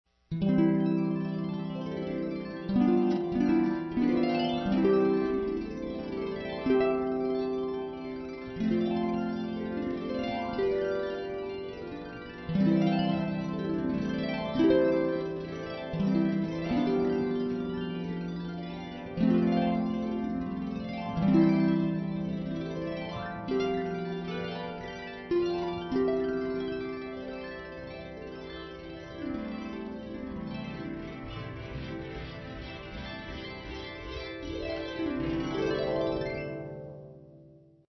Harp Music